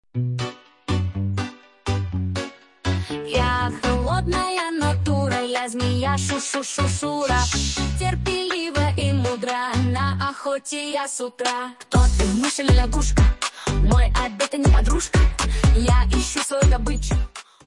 Фрагмент 1-го варианта исполнения (песня Змеи):